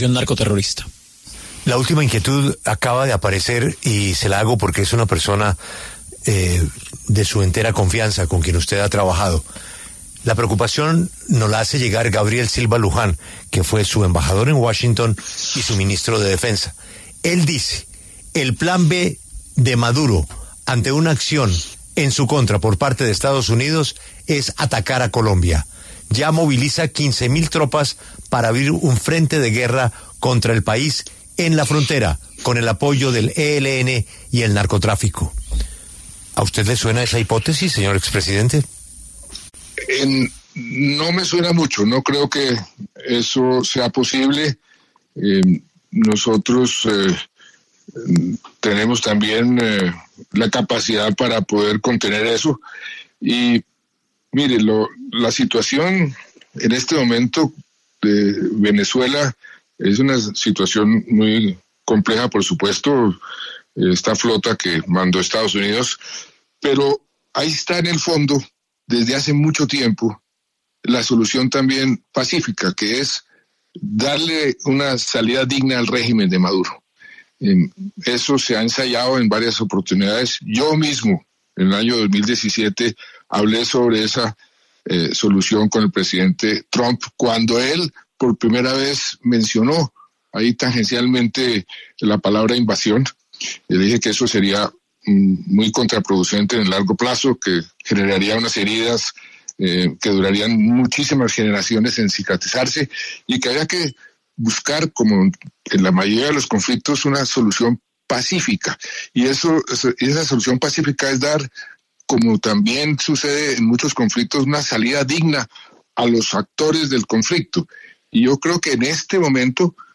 Juan Manuel Santos, expresidente de Colombia y Premio Nobel de Paz, habló con La W acerca de la tensa situación que vive el régimen de Nicolás Maduro con la llegada de parte de la aproximación de la flota de Estados Unidos.